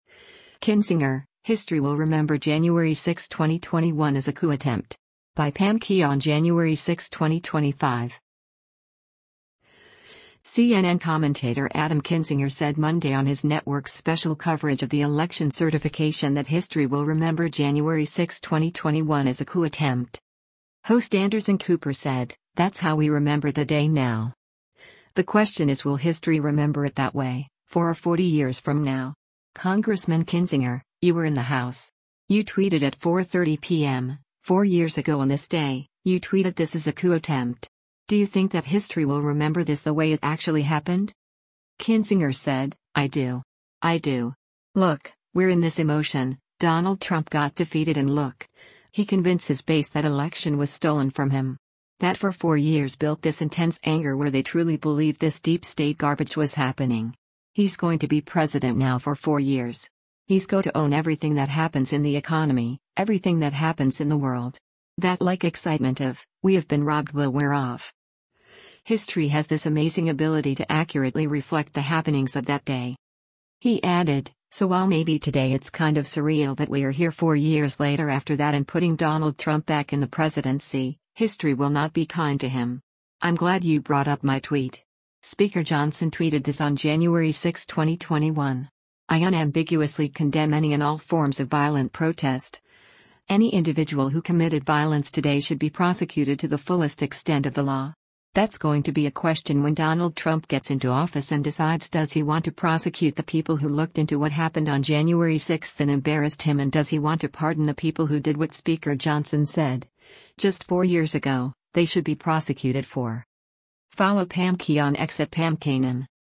CNN commentator Adam Kinzinger said Monday on his network’s special coverage of the election certification that history will remember January 6, 2021 as a “coup attempt.”